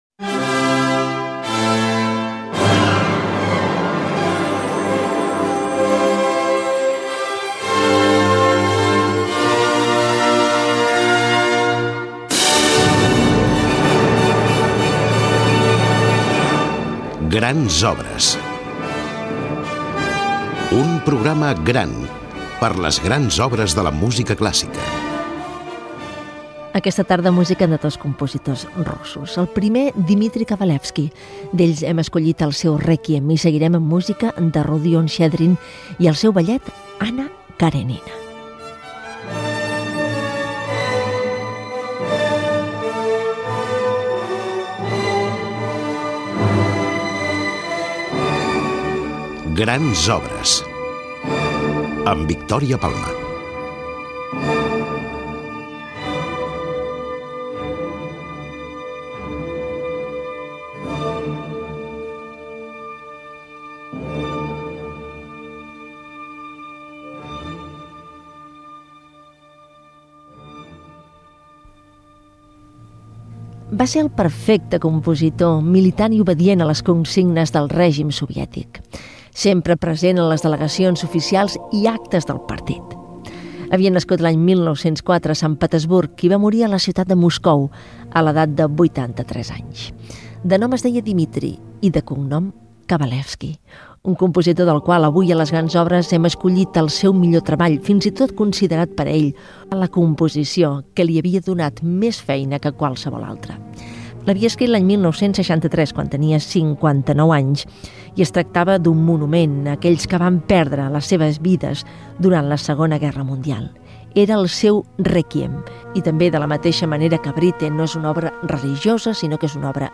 Careta del programa i presentació de la primera obra
Musical